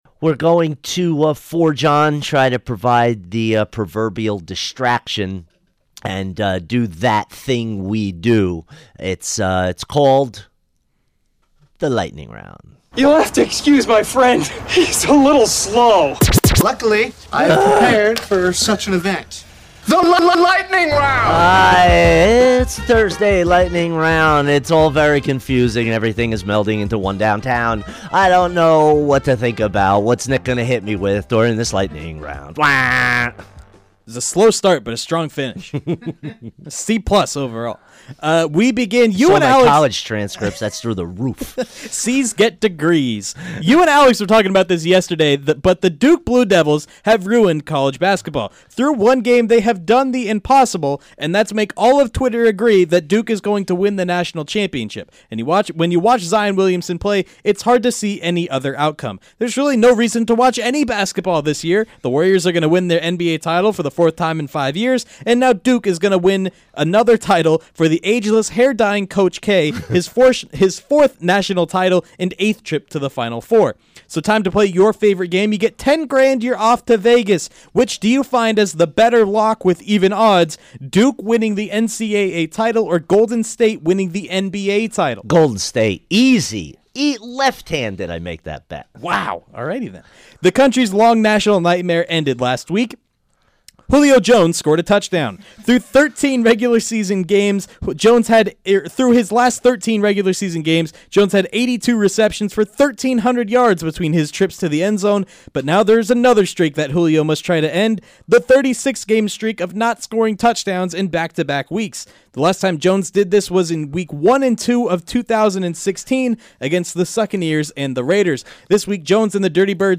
fires through the big sports stories of the day rapid-fire style